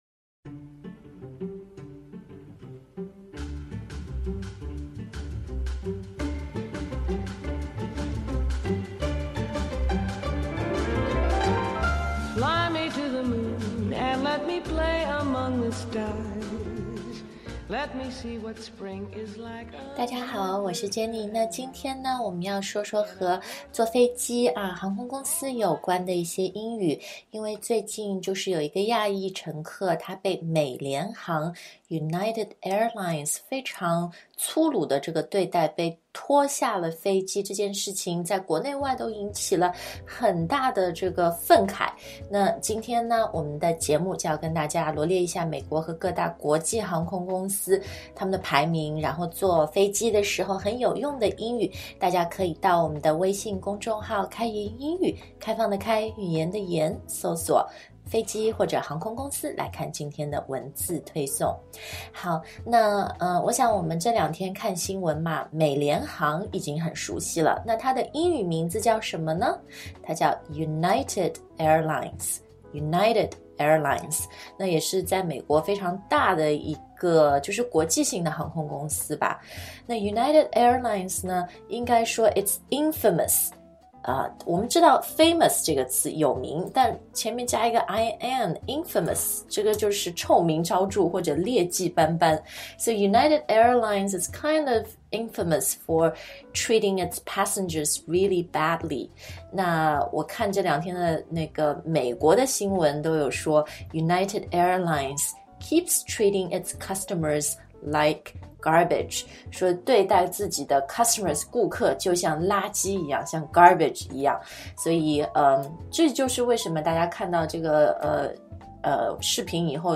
Aisle的发音是[aɪl]， s不发音